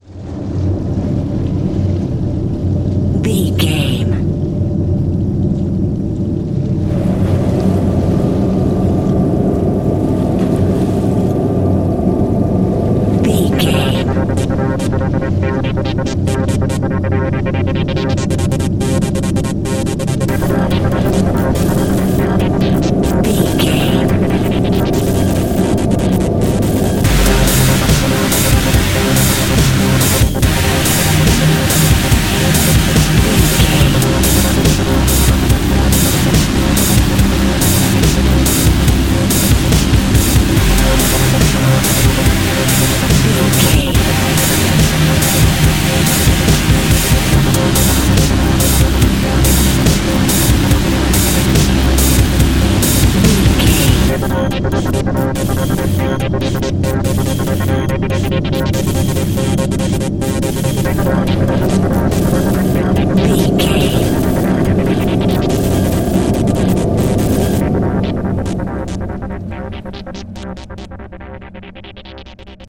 In-crescendo
Thriller
Aeolian/Minor
scary
tension
ominous
dark
suspense
eerie
strings
synth
ambience
pads